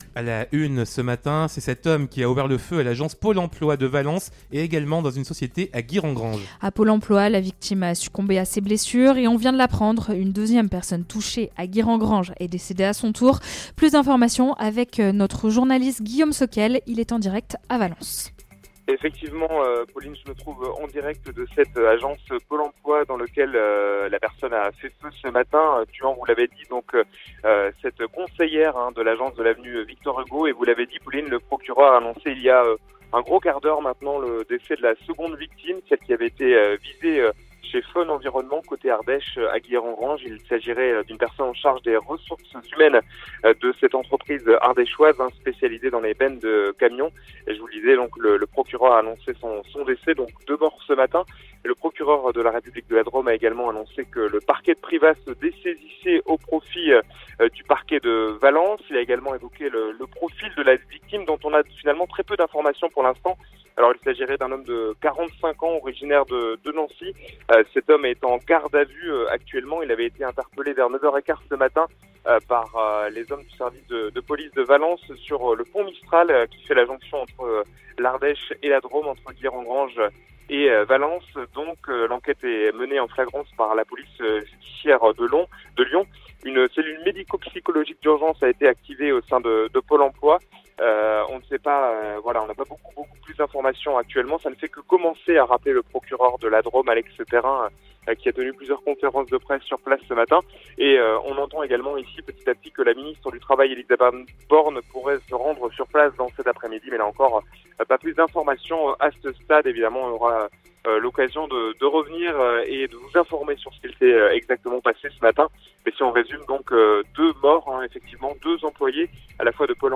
A midi, Chérie FM Vallée du Rhône était en direct à l’agence Pole Emploi de Valence.